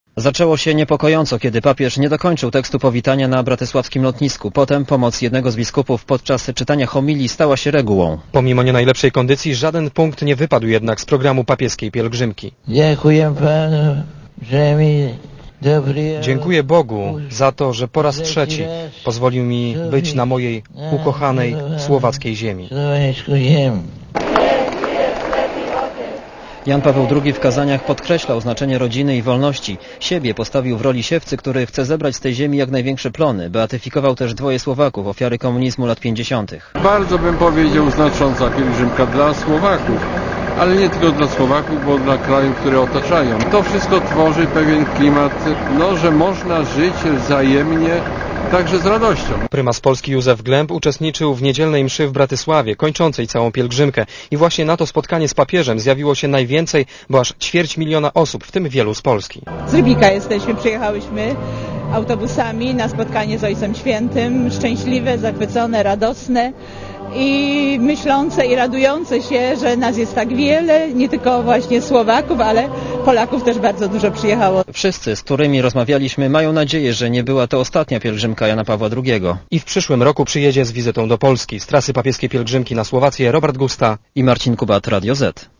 Posłuchaj relacji korespondentów Radia Zet z wizyty Jana Pawła II na Słowacji